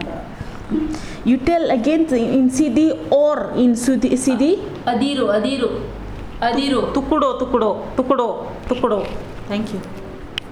Elicitation of words about metal and related
NotesThis is an elicitation of words about metal using the SPPEL Language Documentation Handbook.